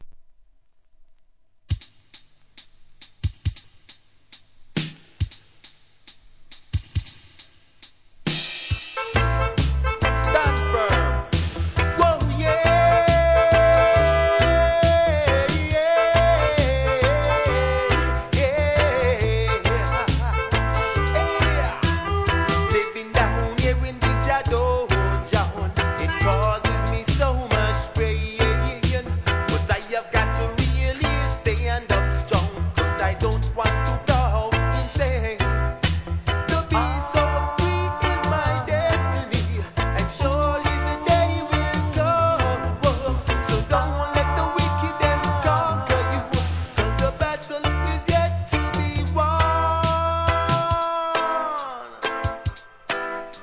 REGGAE
ラスタシンガー